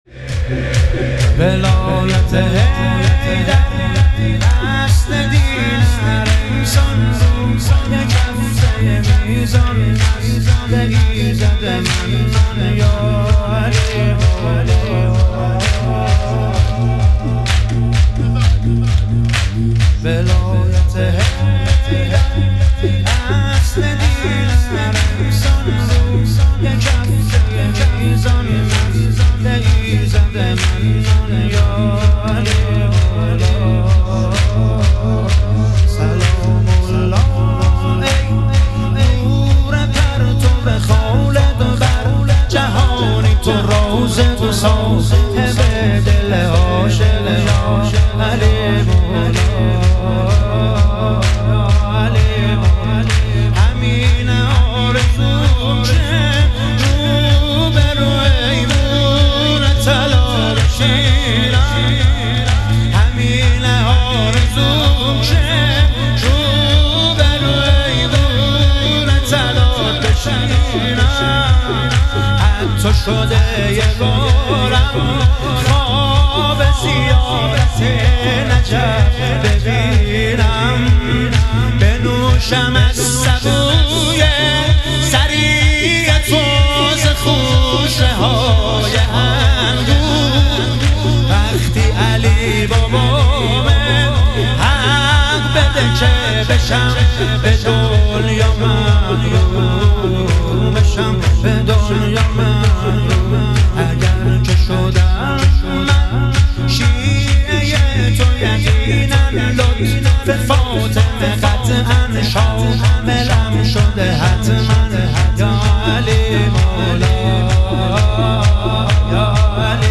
شهادت امام جواد علیه السلام - شور